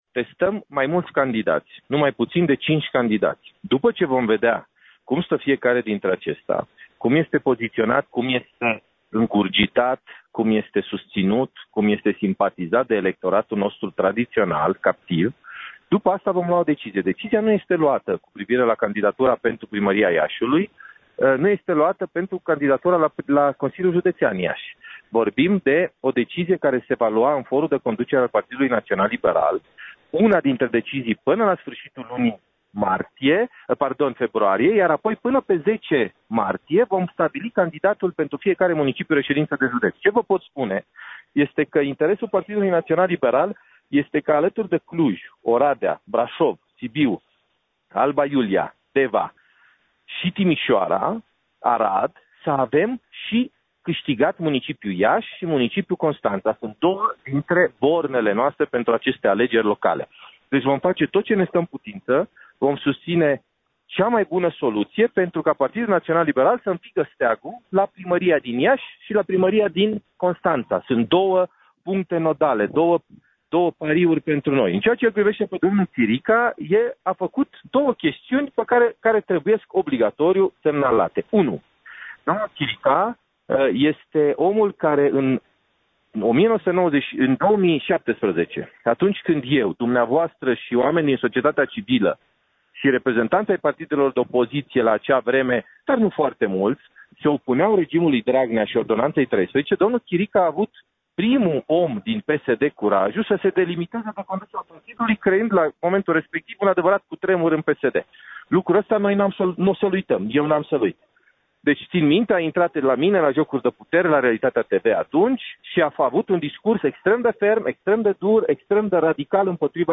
Într-un interviu acordat colegului nostru